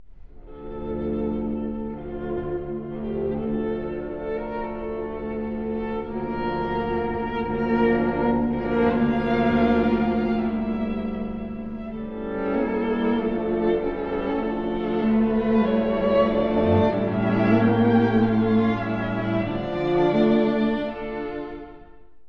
↑古い録音のため聴きづらいかもしれません！（以下同様）
哀愁を帯びたアダージョ。